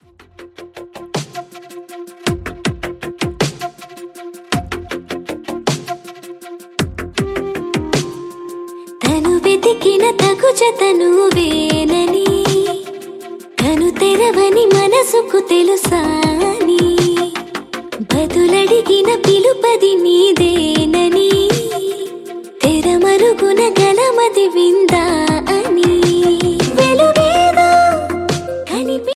letest flute ringtone download | love song ringtone
romantic ringtone download